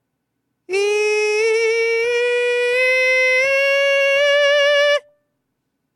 音量注意！
頑張って発声してみましたがこんな感じでめちゃくちゃ難しい＆厳しいです・・・
パターン１は『イ』をなるべくそのまま維持して発声しようとした音源で、パターン２は高くなるにしたがって『エ』を意識して発声した音源です。